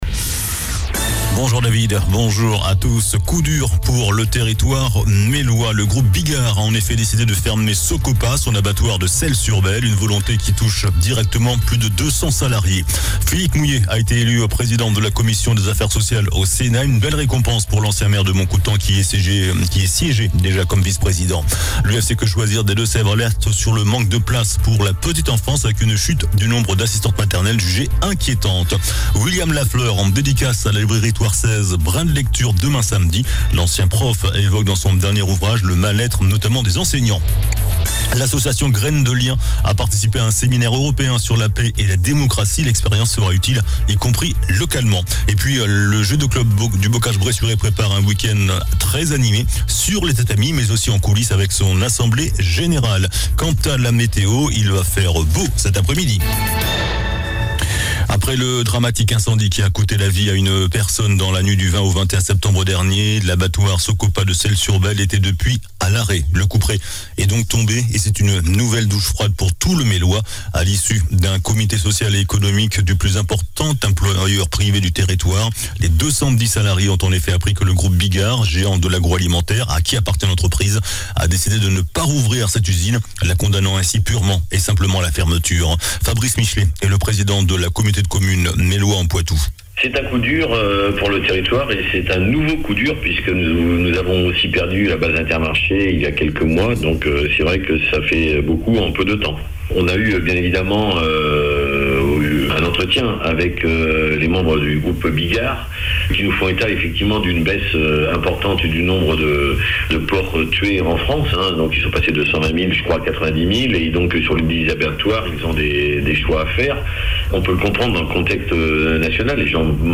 JOURNAL DU VENDREDI 06 OCTOBRE ( MIDI )